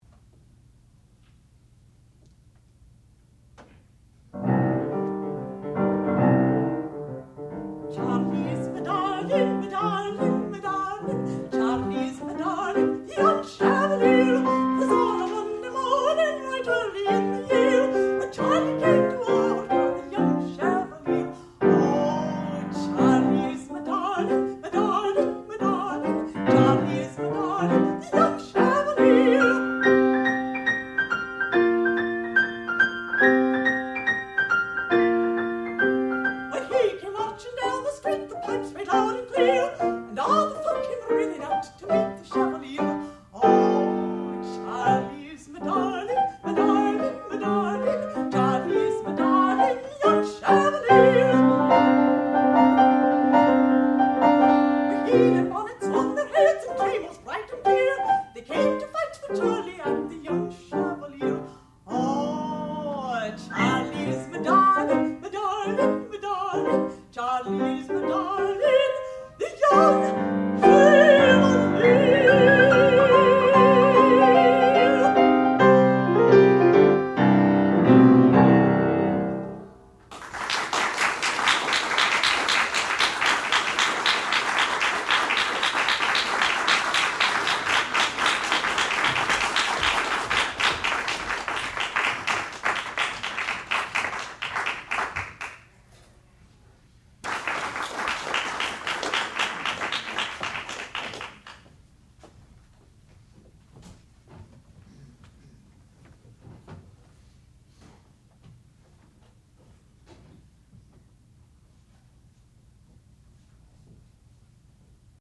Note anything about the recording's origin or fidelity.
Master's Degree Recital